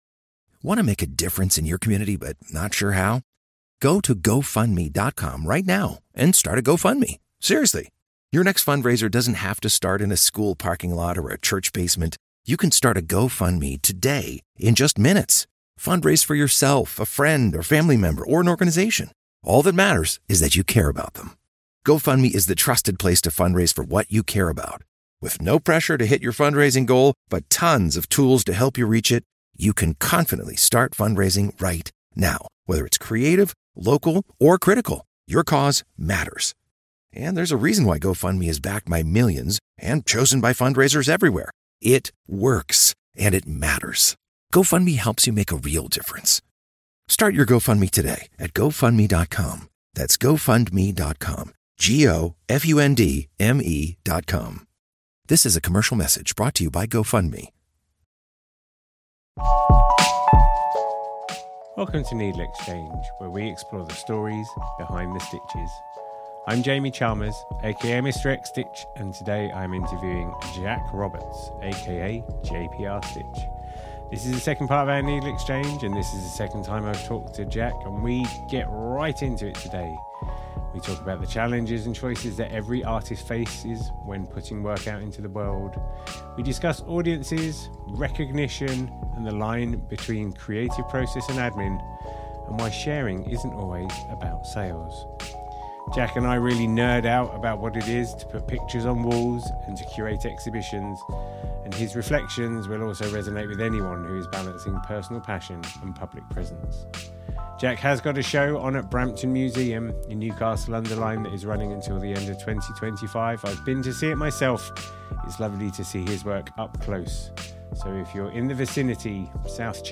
About NeedleXChange: NeedleXChange is a conversation podcast with embroidery and textile artists, exploring their process and practice.